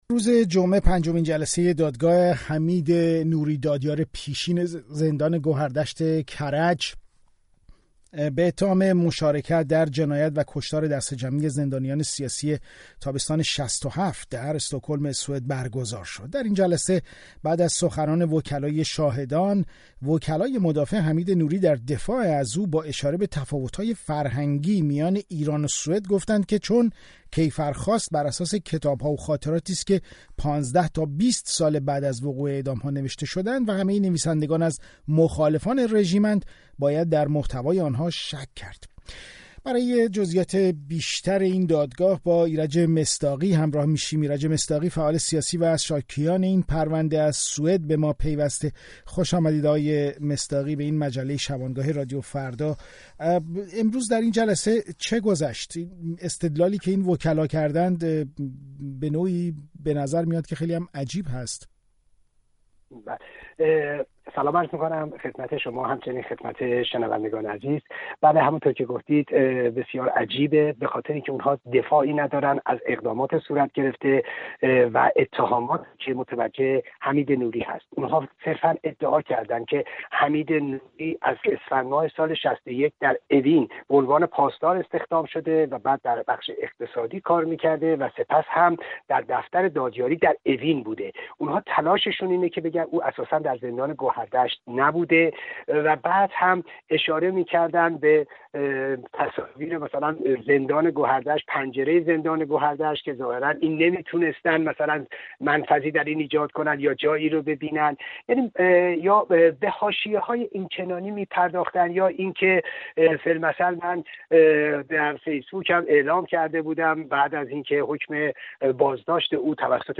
گفت‌وگوی رادیو فردا